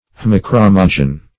haemochromogen.mp3